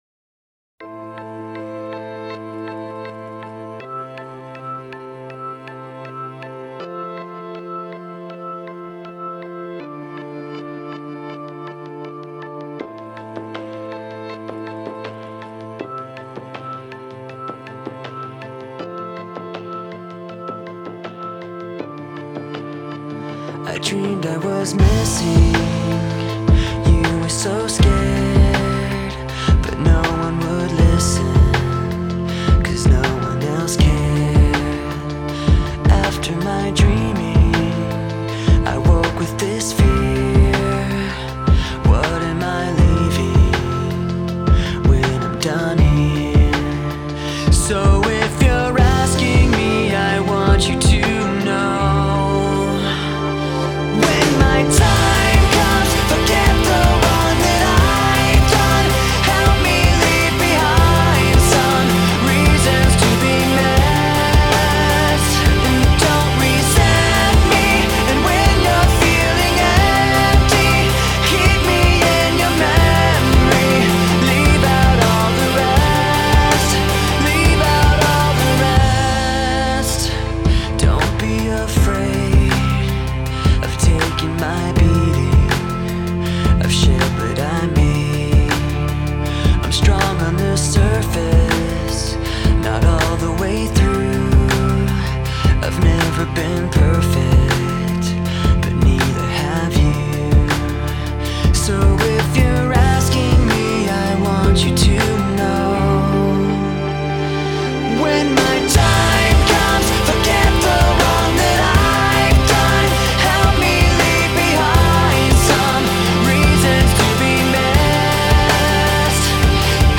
• Жанр: Alternative, Rock